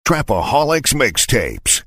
Tm8_Chant37.wav